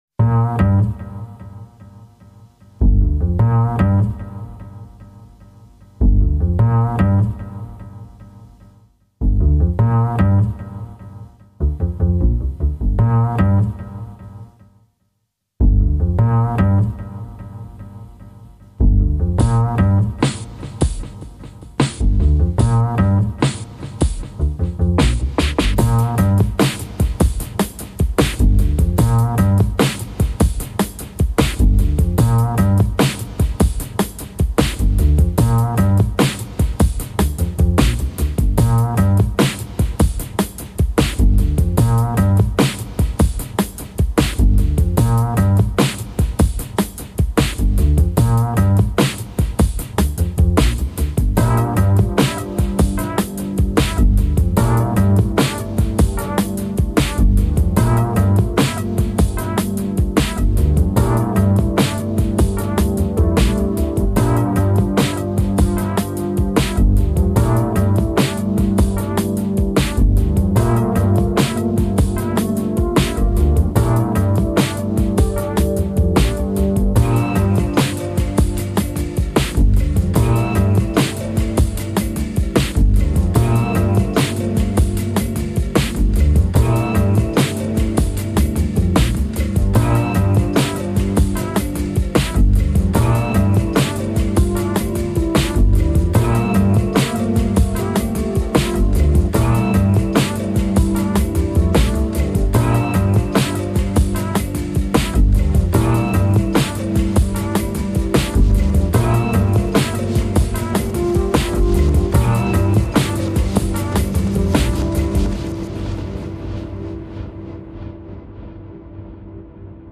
铺陈出轻缓优美的旋律，流泄出法式的浪漫情调.